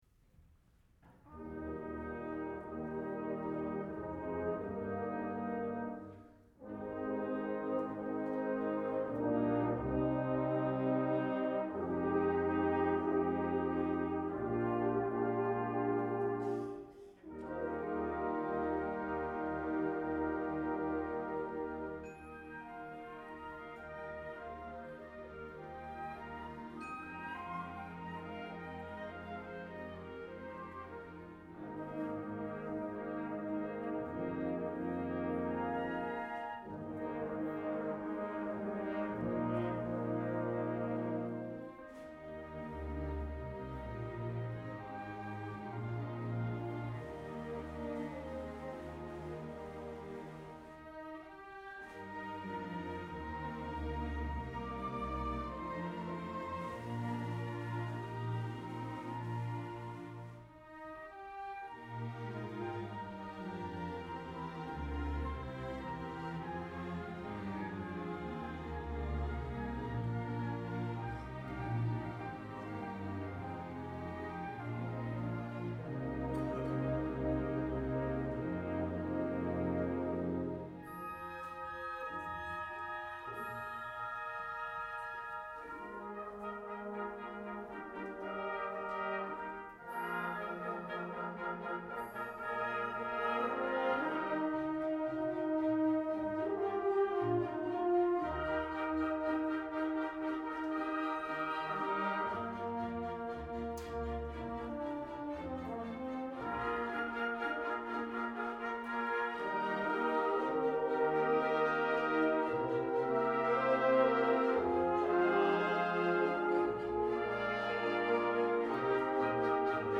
for Orchestra (2009)